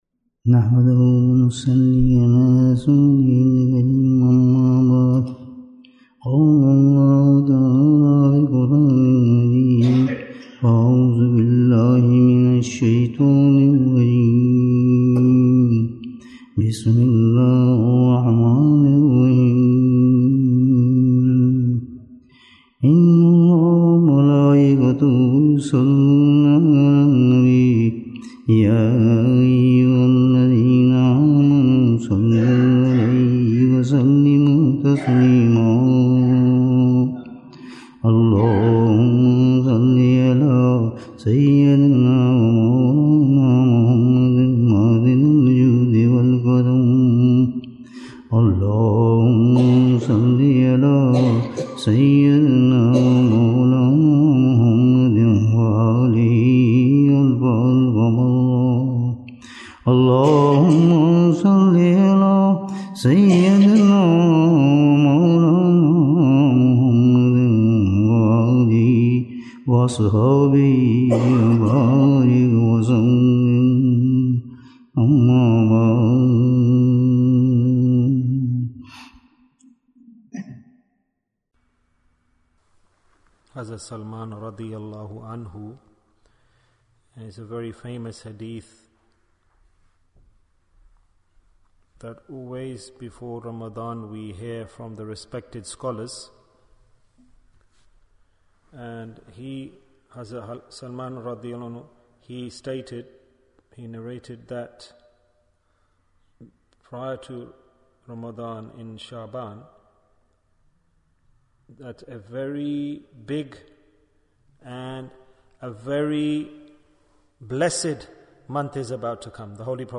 Bayan, 45 minutes30th March, 2023